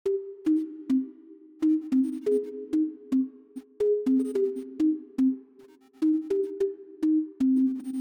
Lo que hemos creado ahora es un efecto de trémolo en el sonido de nuestro pad con una frecuencia de divisiones de 32 notas en función de nuestro tempo.
Pad con tremolo resultante: